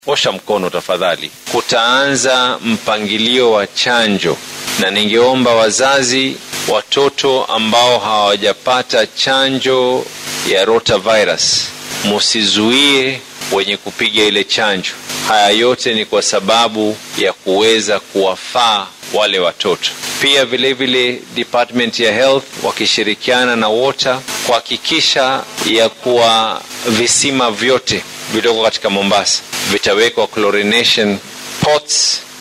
Naasir oo warbaahinta kula hadlay xafiiskiisa ayaa sheegay inuu jiro walaac laga qabo inay kordhaan xaaladaha ka dhasha cudurkan oo loo yaqaano Rotavirus.
Barasaabka-Mombasa.mp3